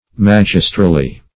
magistrally - definition of magistrally - synonyms, pronunciation, spelling from Free Dictionary Search Result for " magistrally" : The Collaborative International Dictionary of English v.0.48: Magistrally \Mag"is*tral*ly\, adv.
magistrally.mp3